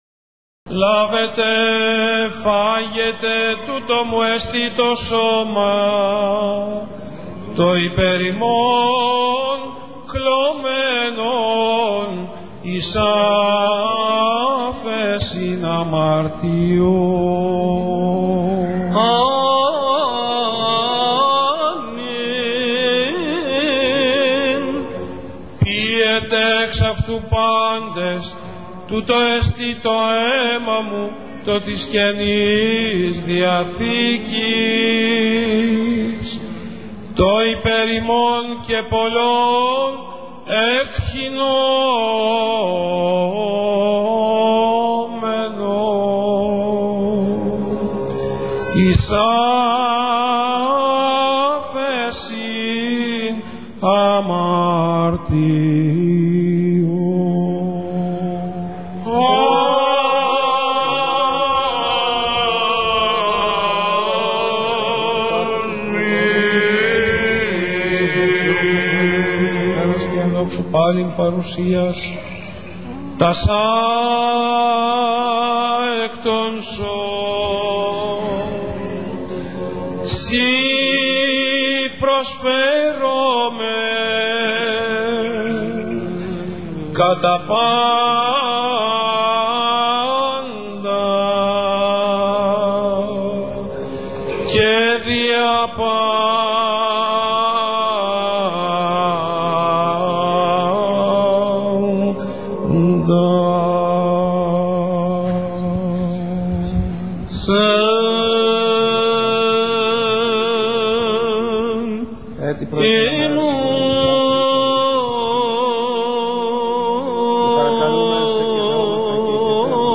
[59] Ἡχητικό τοῦ Σὲ ὑμνοῦμεν κατὰ τὴν διάρκεια τοῦ καθαγιασμοῦ ὑπὸ τοῦ Ἀρχιεπ. Χριστοδούλου (27/6/2004, .Ν. Ἁγ. Ἐλευθερίου Ἀχαρνῶν, μετὰ χειροτονίας):